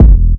Kick Rnb 5.wav